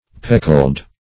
peckled \pec"kled\ (p[e^]k"kl'd)